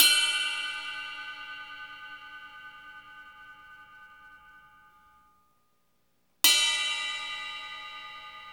CYM ET RID0O.wav